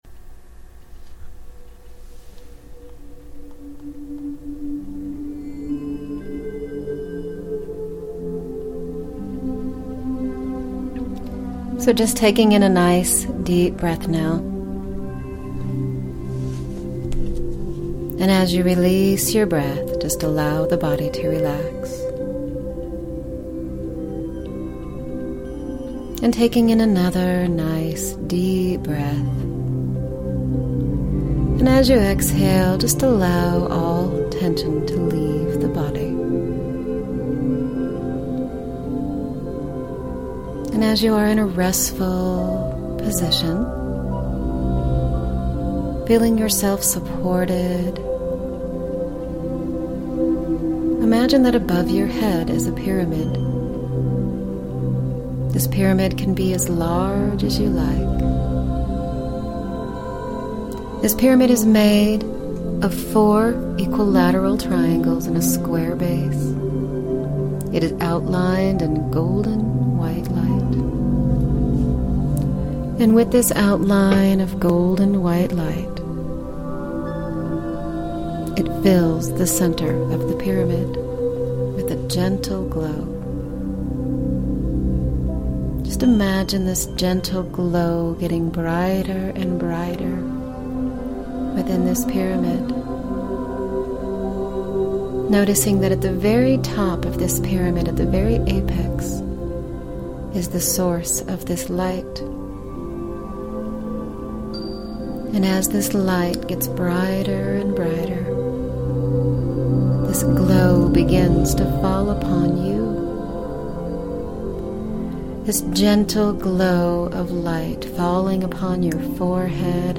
This is a wonderful guided meditation that will help you learn more about visualization, too. The intention is to connect within your own inner wisdom and inner world to align with your divinity and with Spirit.